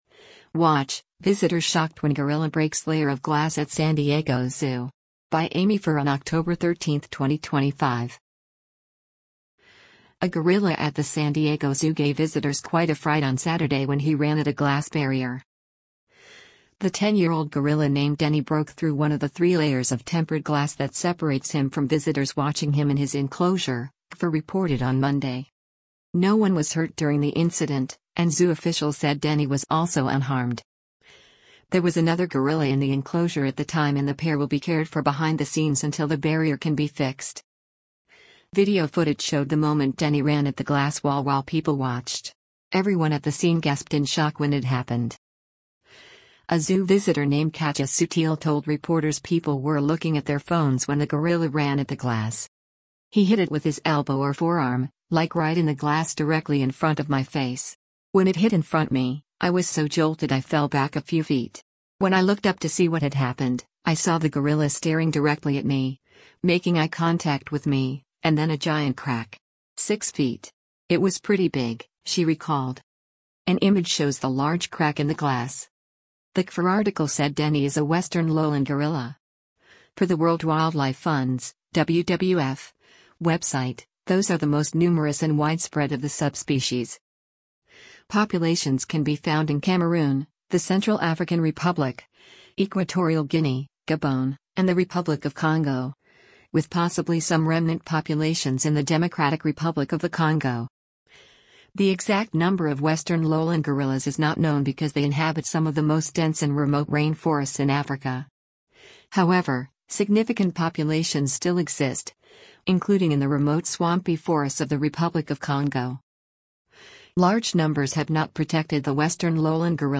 Everyone at the scene gasped in shock when it happened.